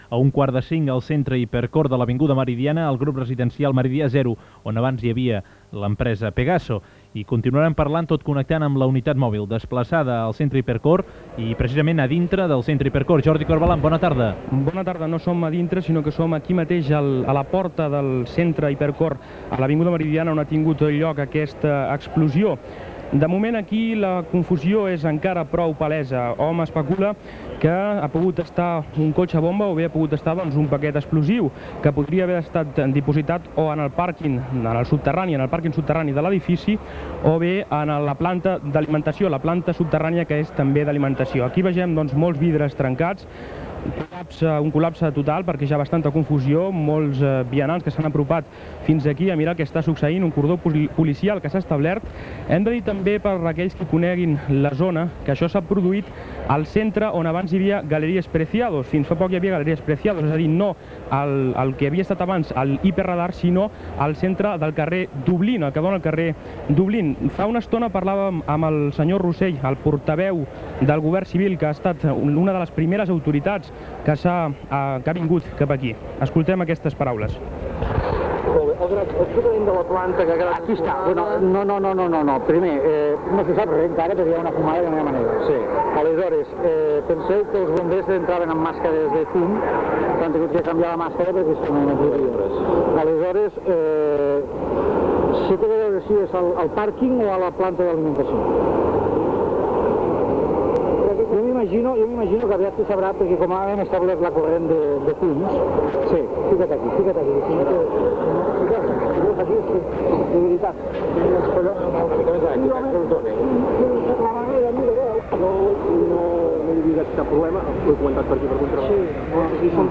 Informació en directe de l'atemptat d'Hipercor a Barcelona, poc després de l'explosió de la bomba a dins del seu aparcament. Declaracions del governador civil de Barcelona.
Informatiu